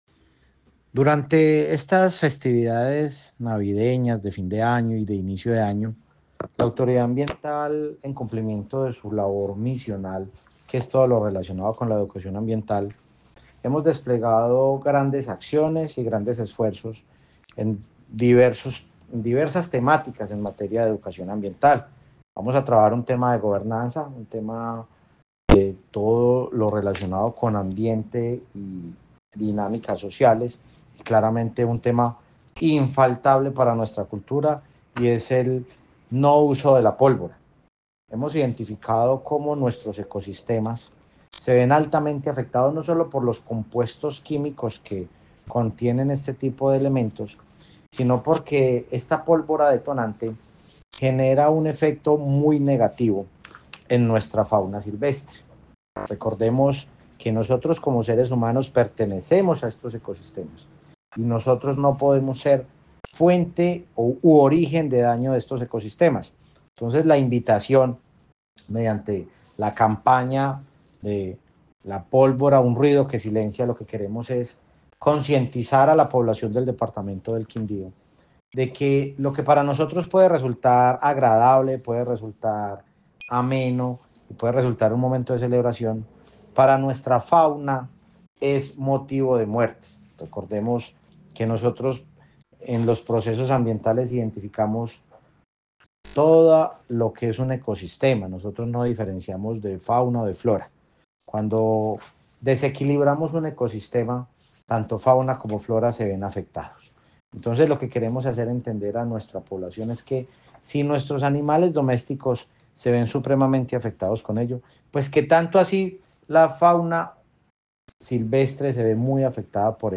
Juan Esteban Cortés, director CRQ